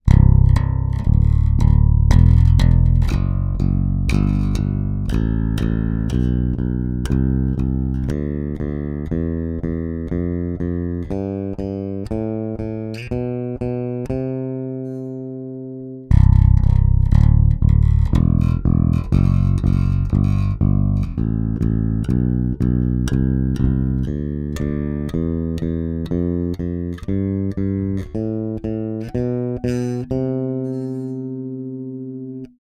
So, hier mal Sample: Ein Spector SSD (CZ), mit EMG 40J, beide Pickups, EMG BTS (VollBoost), zuerst der B118, dann der B125 Balance Pot. Saiten sind D‘addario Pro Steels 43-127. your_browser_is_not_able_to_play_this_audio